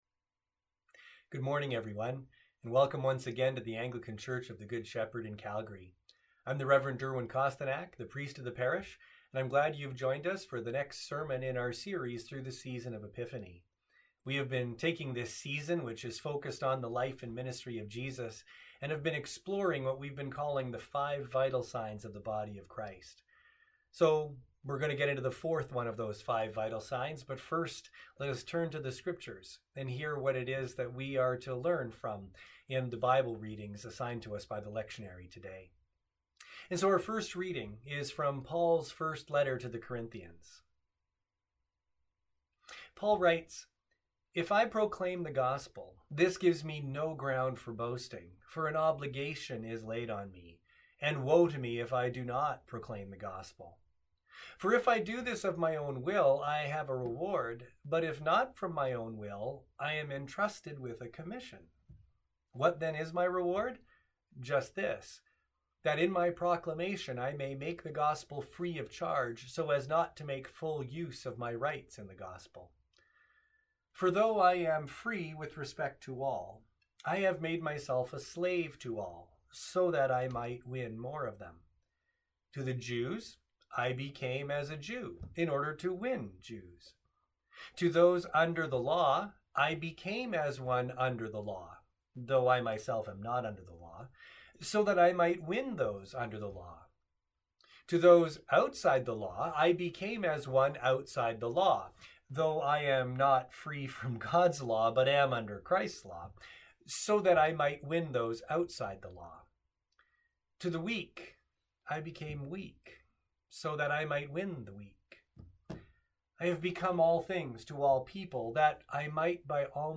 Sermons | The Church of the Good Shepherd